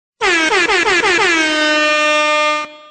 DJ Airhorn